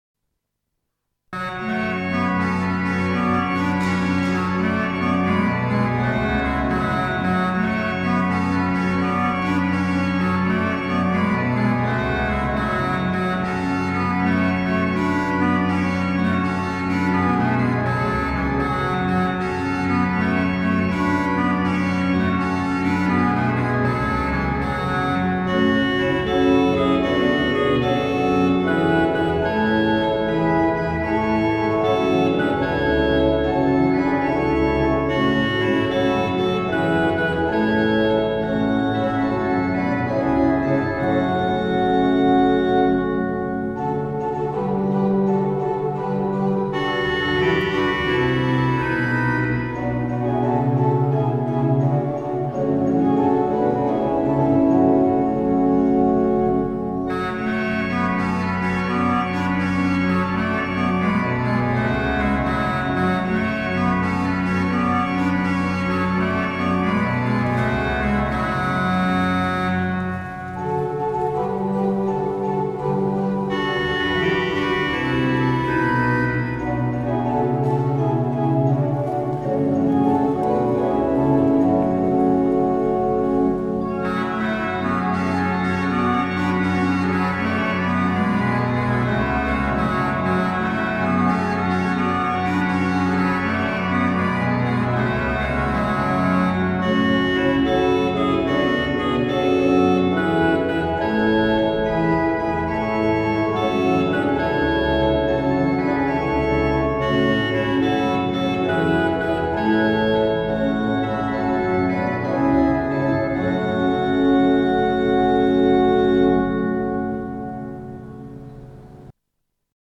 bande son